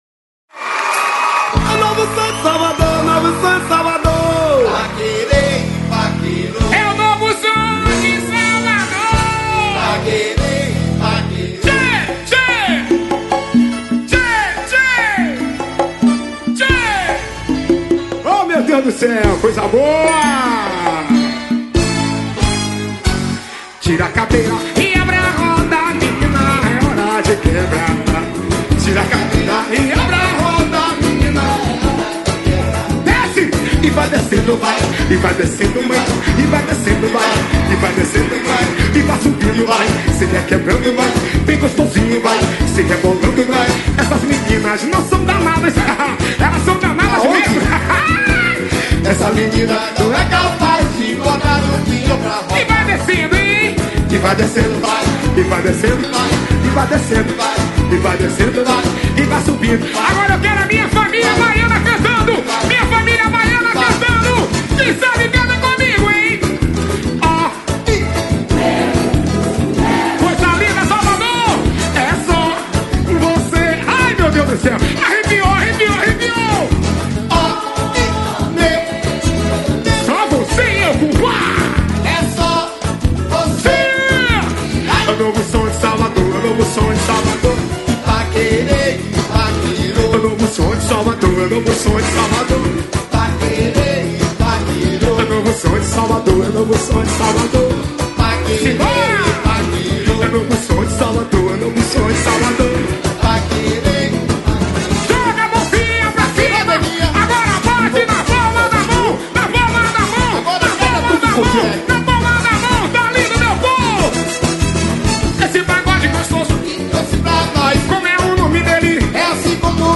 Axe